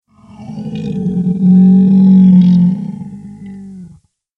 دانلود آهنگ جیغ گربه بزرگ از افکت صوتی انسان و موجودات زنده
دانلود صدای جیغ گربه بزرگ از ساعد نیوز با لینک مستقیم و کیفیت بالا
جلوه های صوتی